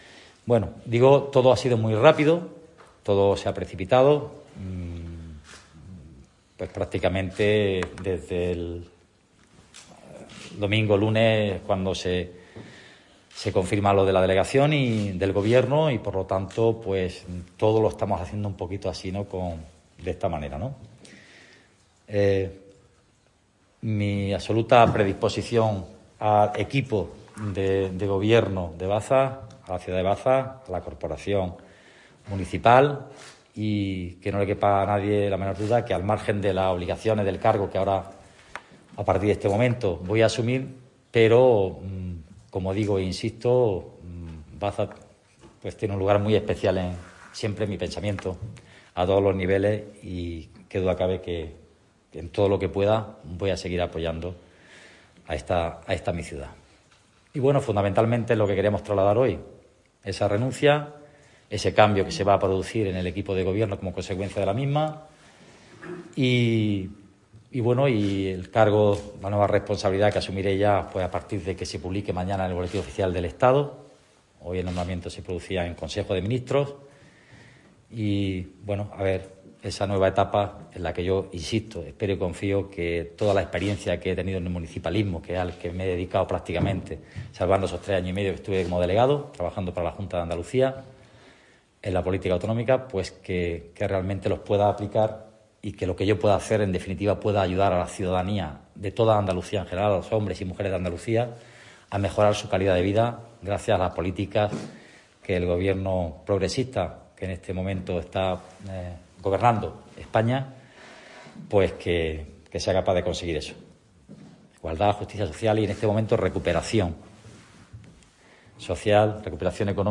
En su última rueda de prensa como alcalde de la ciudad que lo ha visto crecer en política y con la que abre una nueva etapa, Fernández ha agradecido al presidente del Gobierno su nombramiento, que asume con enorme responsabilidad y con el compromiso de seguir apostando por las políticas de impulso del municipalismo.